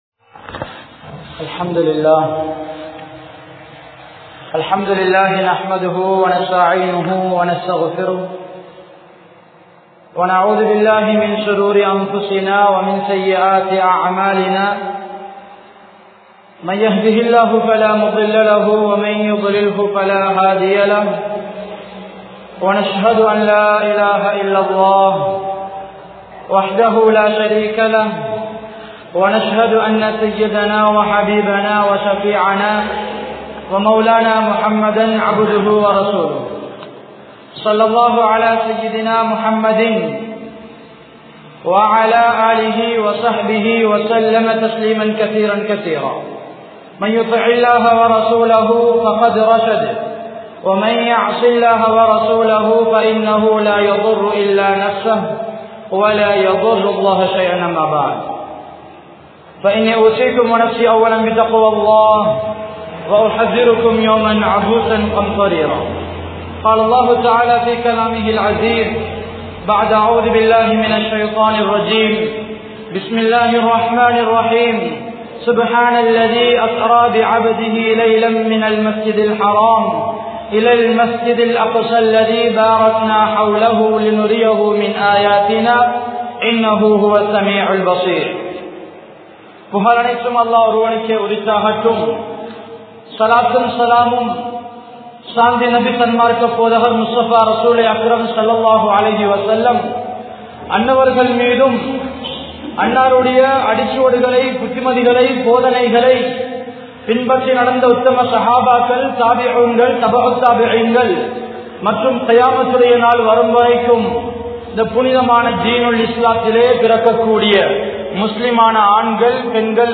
Nabiyavarkalin Miraj Payanam(நபியவர்களின் மிஃராஜ் பயணம்) | Audio Bayans | All Ceylon Muslim Youth Community | Addalaichenai